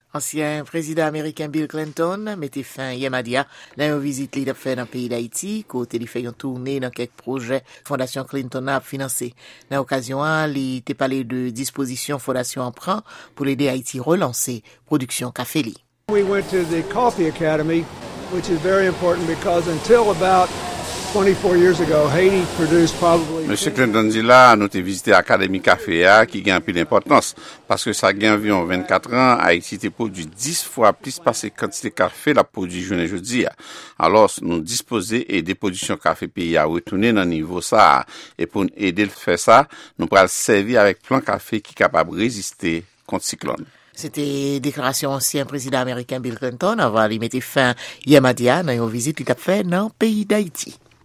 Repòtaj sou vizit ansyen Prezidan ameriken Bill Clinton ann Ayiti (17-18 Fev. 2014).